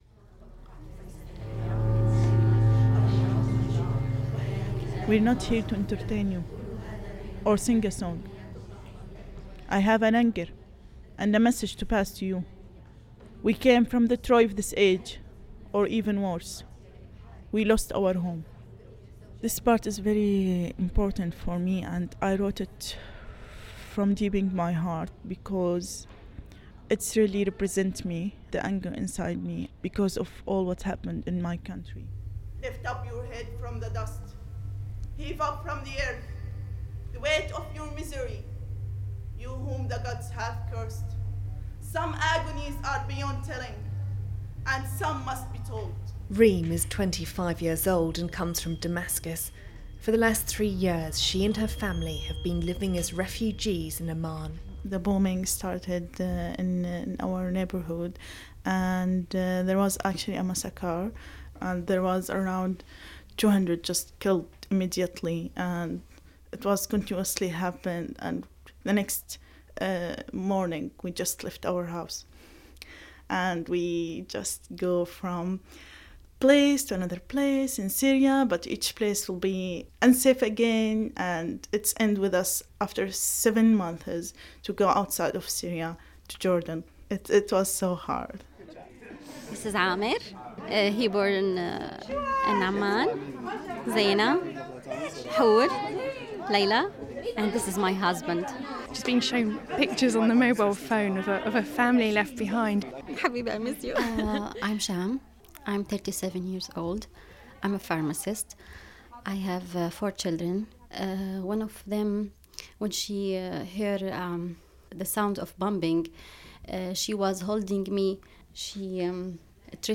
Says a Syrian woman appearing in a modern adaptation of Euripides' anti-war play "The Trojan Women". "Queens of Syria" features 13 female refugees who skilfully mix their own experiences of war and bitter exile with the ancient Greek text. The play is being performed before going on a nationwide tour.